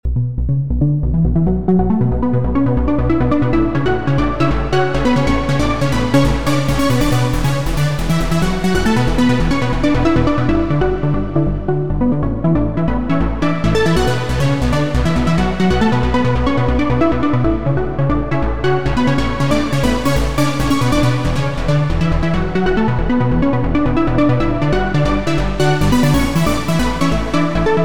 Trance песочница (крутим суперпилы на всём подряд)
Или просто юзайте sylenth1/spire)) и не парьтесь ну вот не то... у меня лично не выходит повторить гиперпилу остируса на осирусе...не плывет она так в ширь в два клика) да и проблема, думаю, временная... мало донатим)